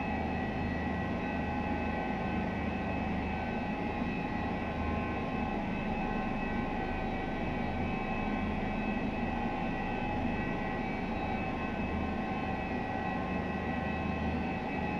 H145_Flight_Idle1-left.wav